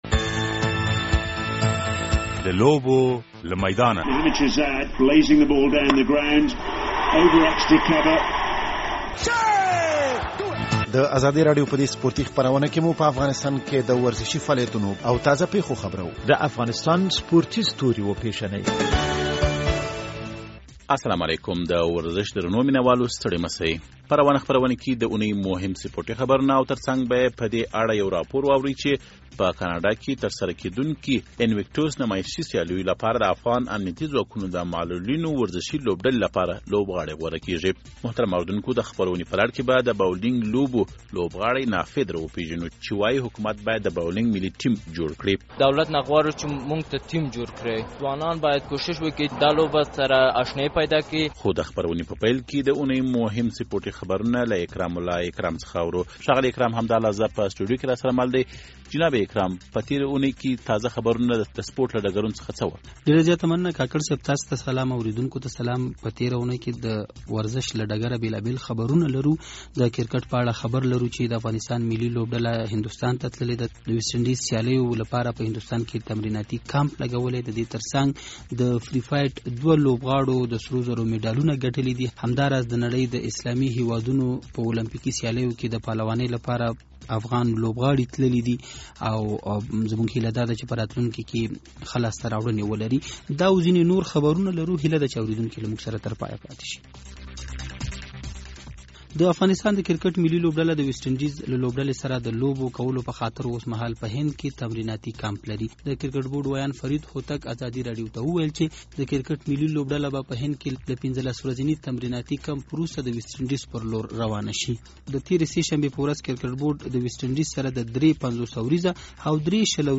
په نننۍ ورزشي خپرونه کې د اوونۍ مهم سپورټي خبرونه او تر څنګ به یې په دي اړه یو ځانګړی راپور واورئ....